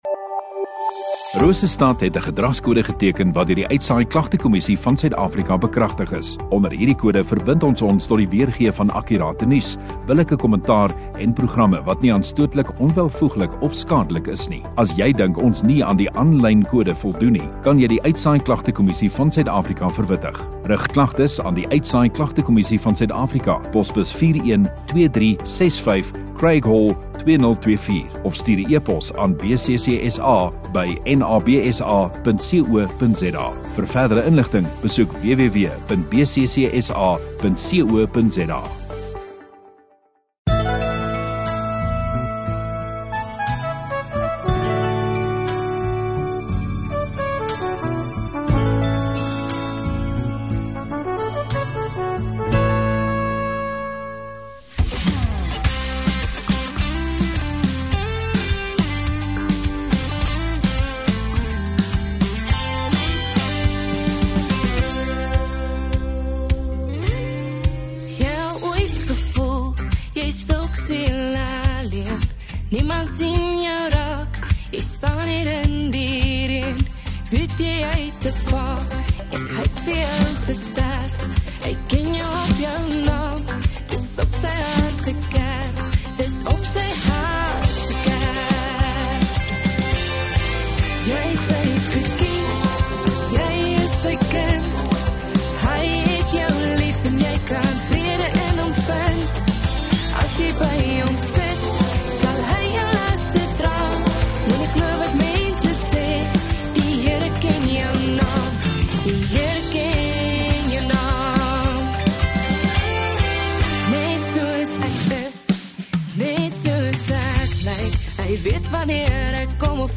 10 Dec Dinsdag Oggenddiens